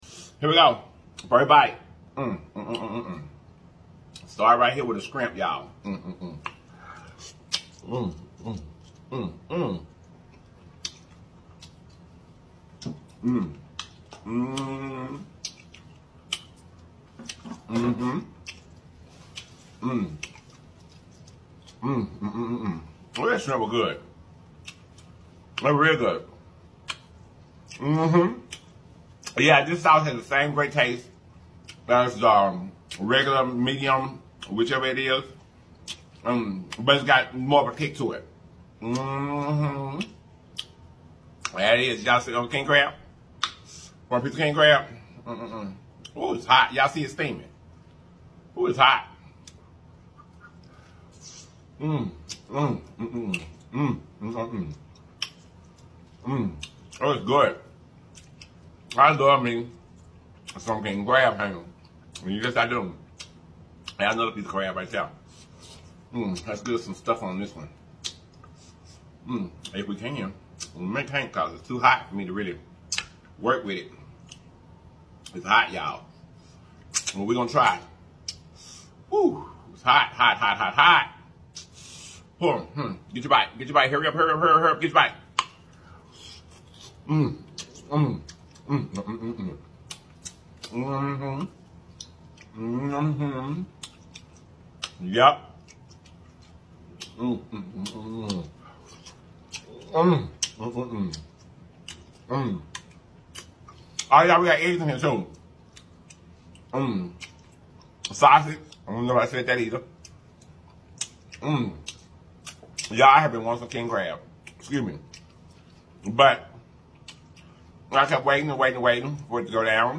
DESHELLED SEAFOOD BOIL MUKBANG 먹방